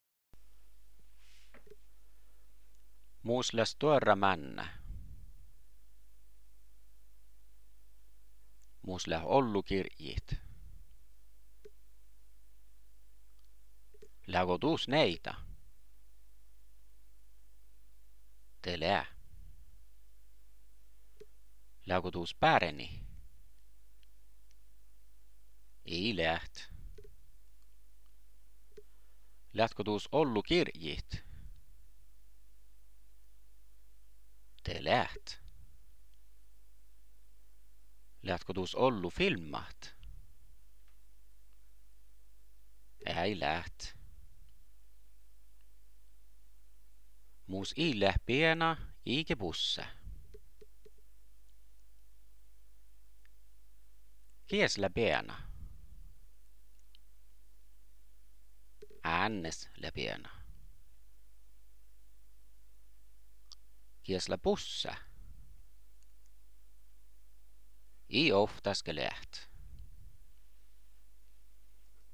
Jietnafiillat: Teavsttat – Lydfiler: Tekster som er lest inn.